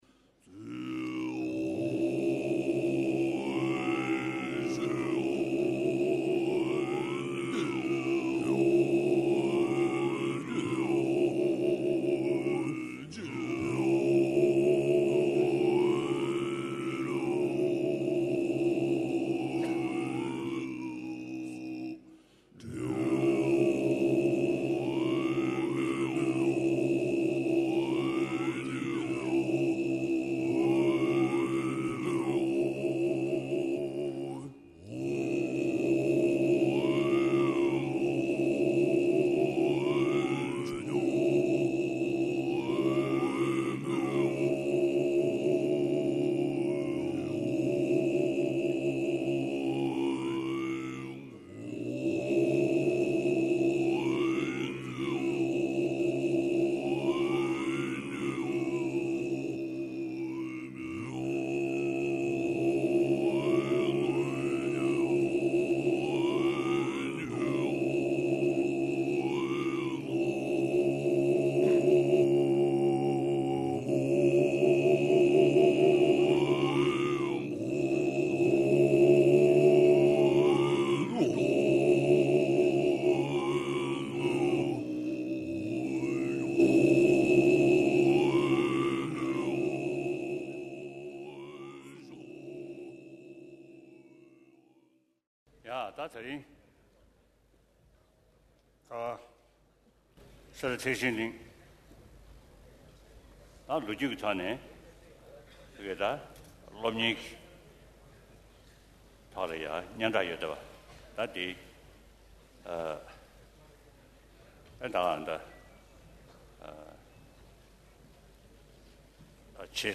Lama Atisha Dipamkara 2013 Jangchup Lamrim Multimedia Archive This archive contains photos, video, and audio-only recordings of the 2013 Jangchup Lamrim Teachings by His Holiness the Dalai Lama at Sera Jey Monastery, Bylakuppe, India.